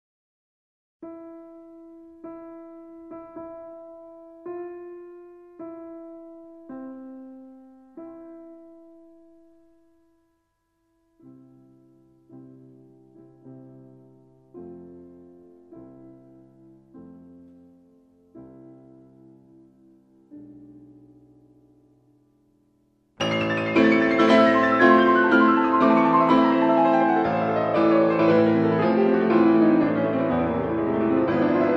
そして、フェルマータ（緑）。
興味深いことに、２小節目の左手部、右手部と息を合わせるように、休符にまでフェルマータ示されています。